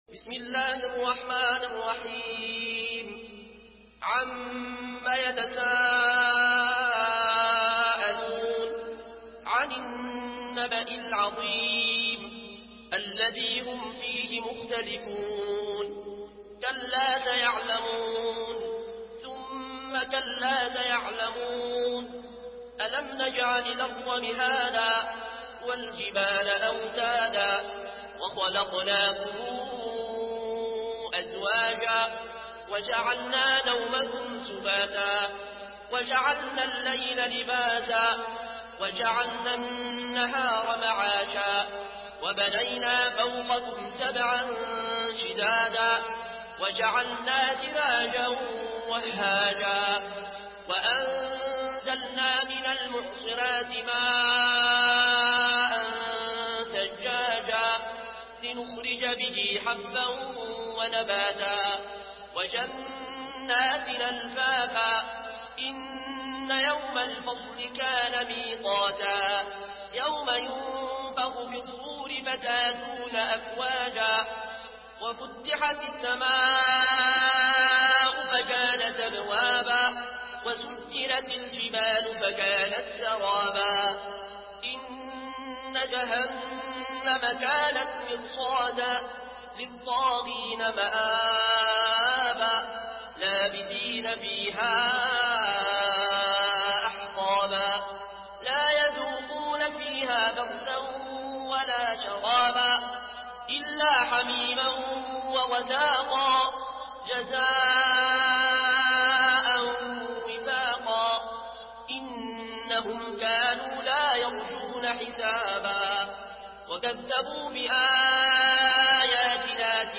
Murattal Warsh An Nafi From Al-Azraq way